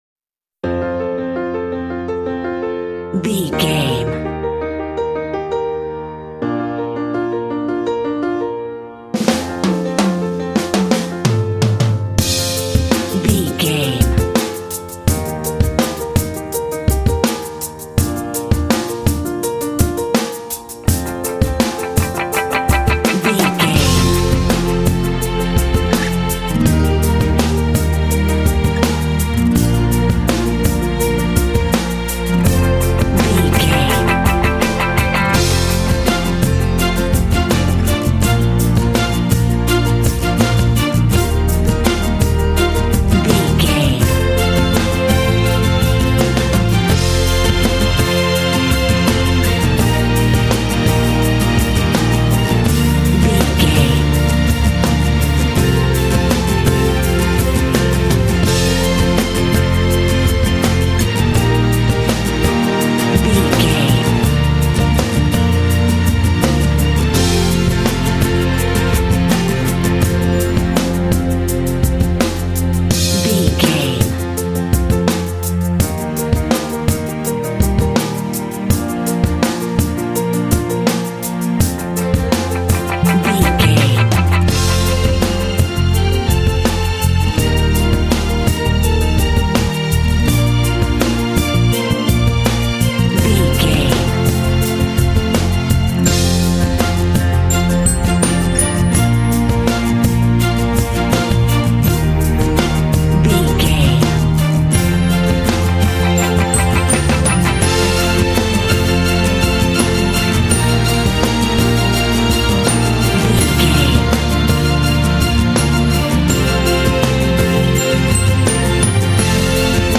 This inspiring track is ideal for celebrating achievements.
Ionian/Major
optimistic
motivational
happy
bright
piano
drums
electric guitar
acoustic guitar
bass guitar
strings
pop
contemporary underscore
rock